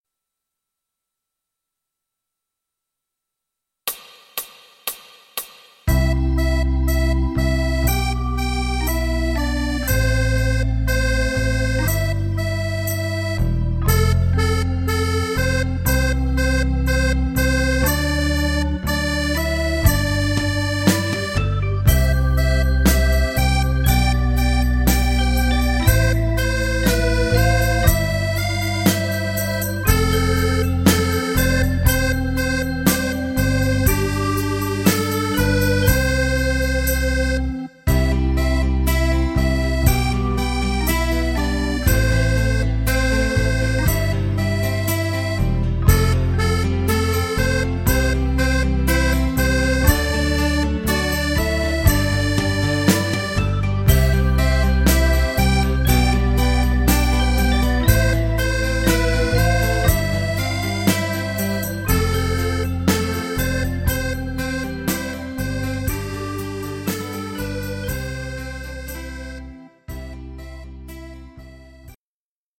Modale Ionica